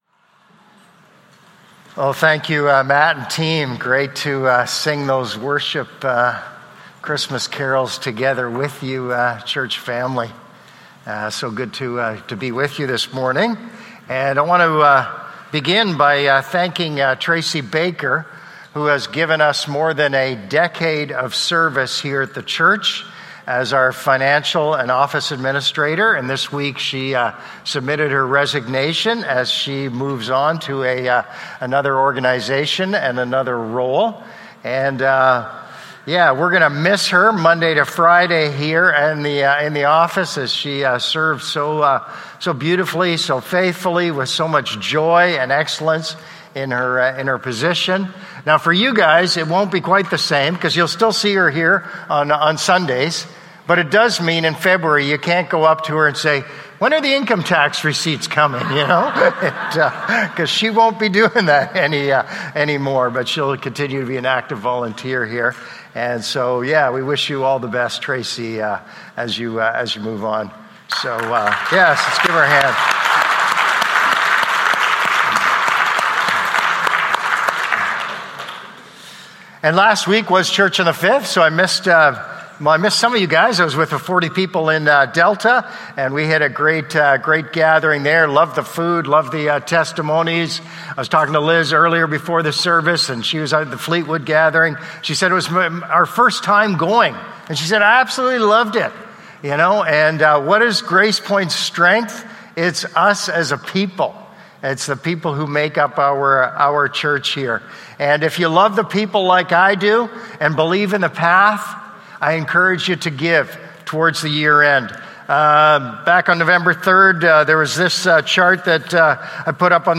Sermons | Gracepoint Community Church